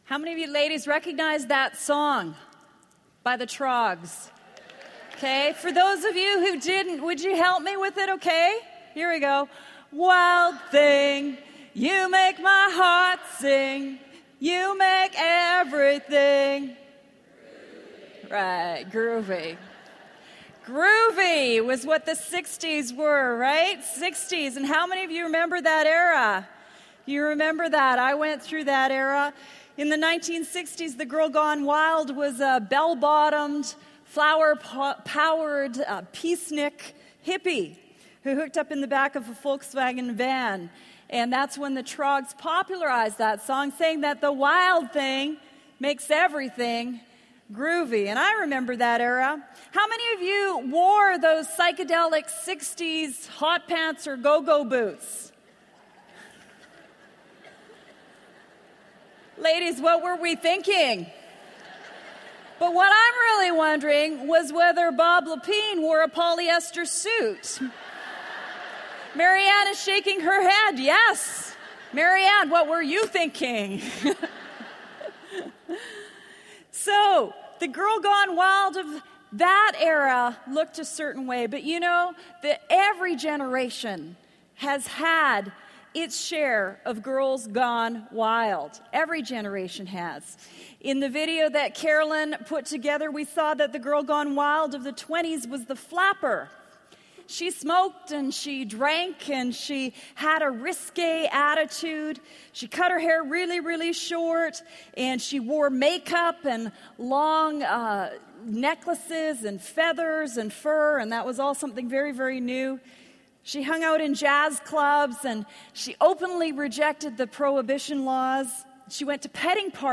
A True Woman Chooses Wisdom | True Woman '10 Fort Worth | Events | Revive Our Hearts